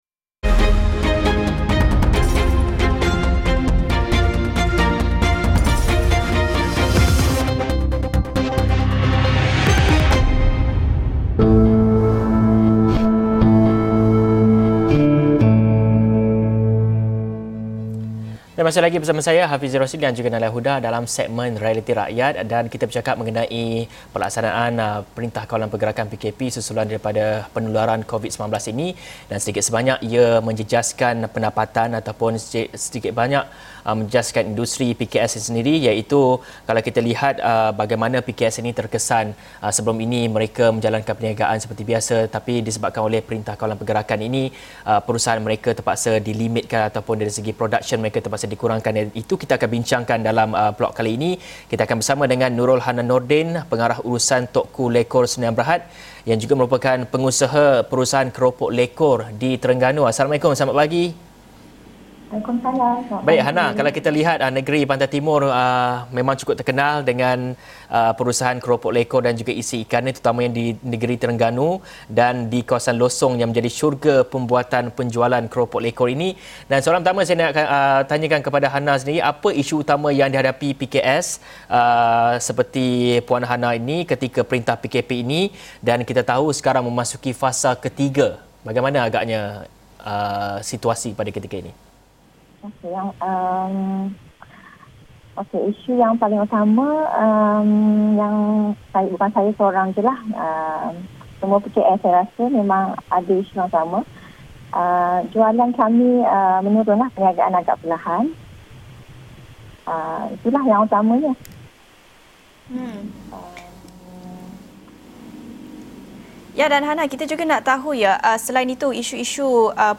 Episod ini telah disiarkan secara langsung dalam program AWANI Pagi, di saluran 501 Astro AWANI, jam 8:30 pagi.